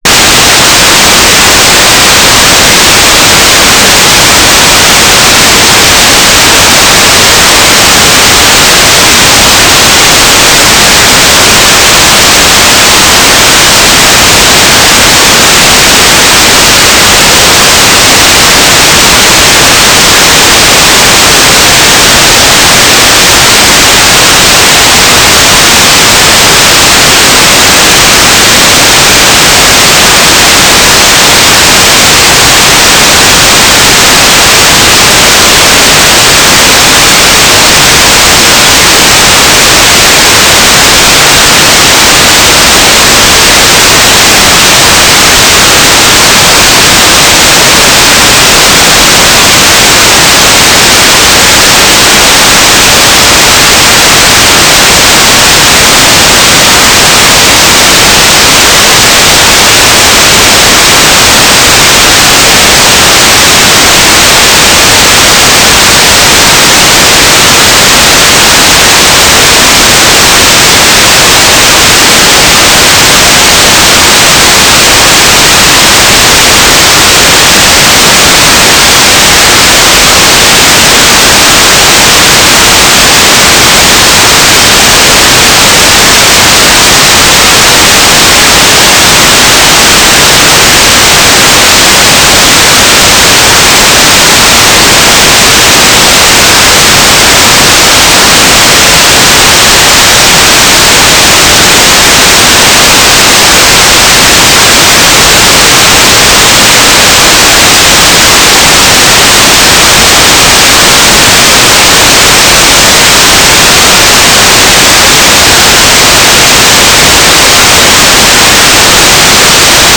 "transmitter_description": "GMSK9k6 AX25 ESEO TLM",
"transmitter_mode": "GMSK",